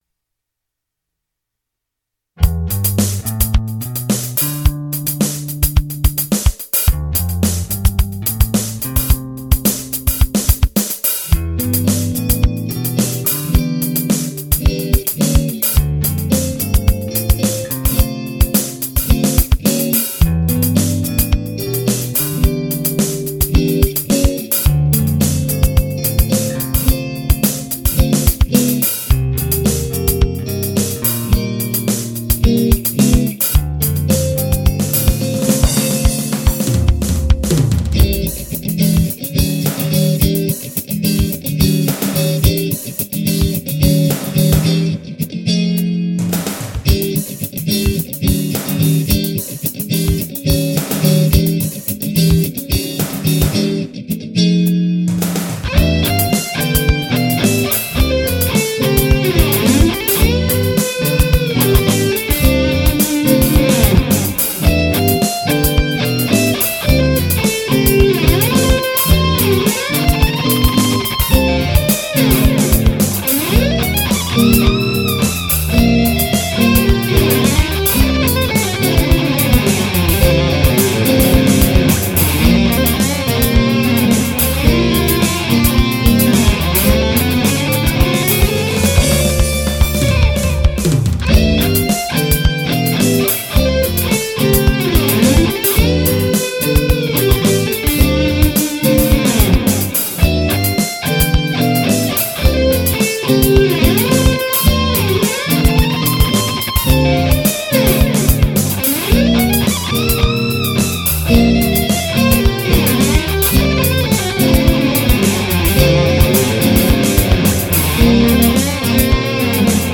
Drums: Dr. Rhythm Drum Machine